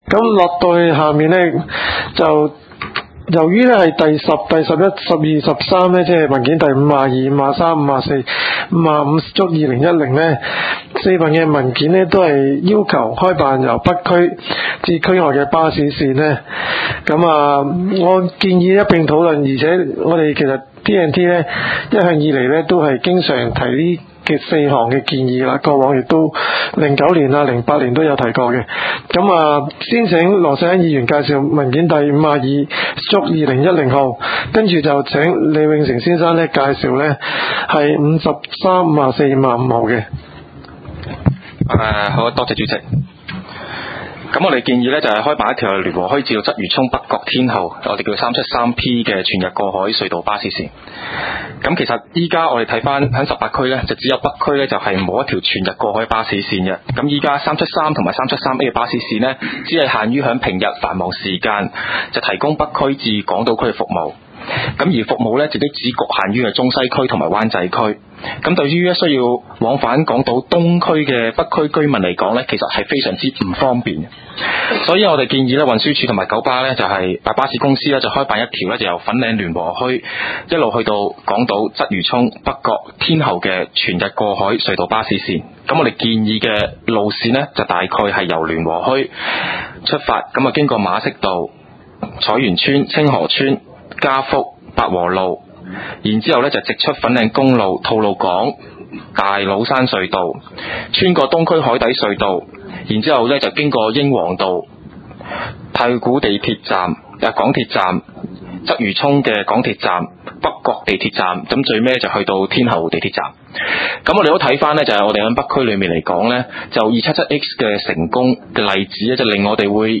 交通及運輸委員會第19次會議紀錄